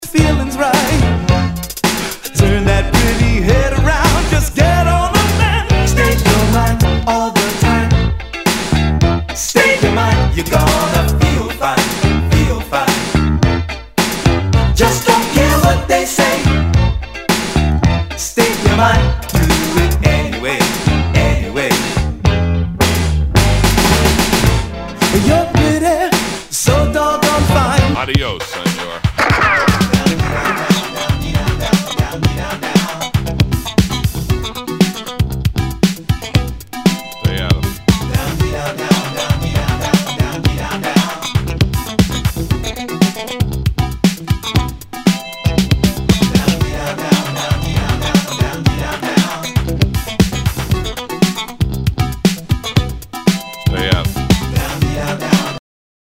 SOUL/FUNK/DISCO
ナイス！ファンク！